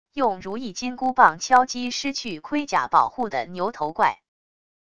用如意金箍棒敲击失去盔甲保护的牛头怪wav音频